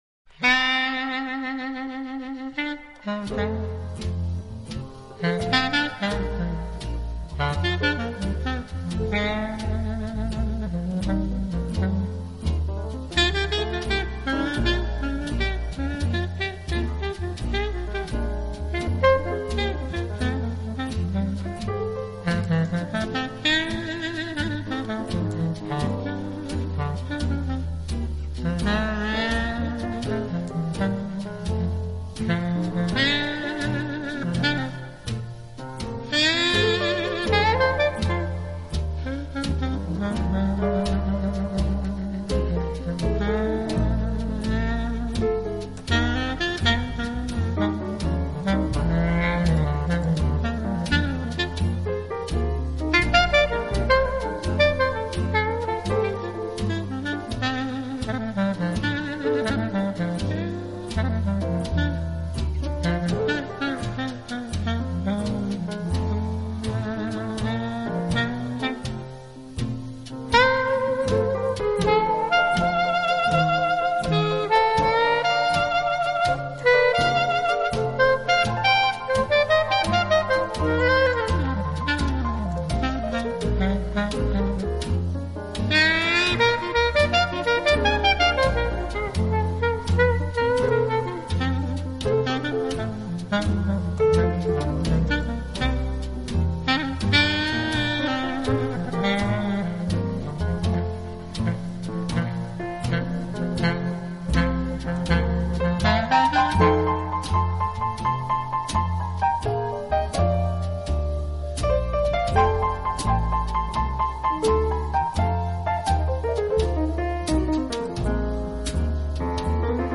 音乐风格：Dixieland 、Swing
这张专辑风格轻快，带人回到大乐队时代，在那些舞动节拍里起舞。